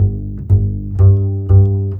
Rock-Pop 11 Bass 06.wav